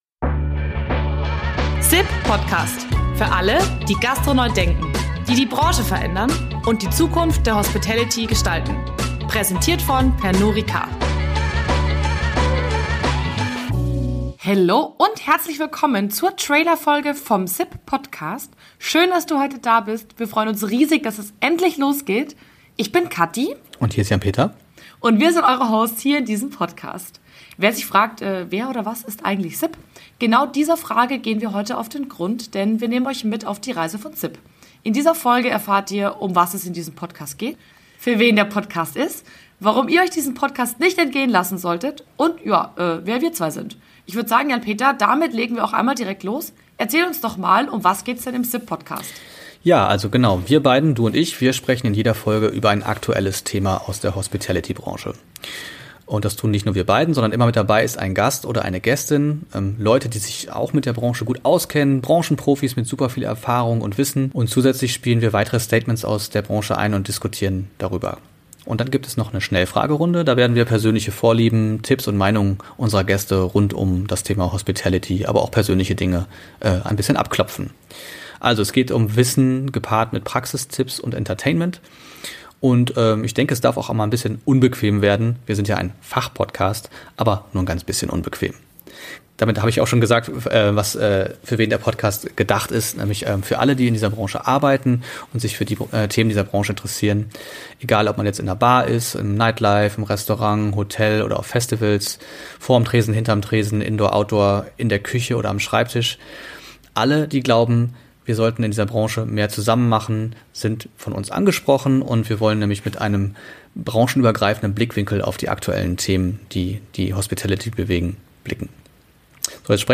In der Trailerfolge erzählen wir euch, auf was ihr euch im SIP.Podcast freuen dürft.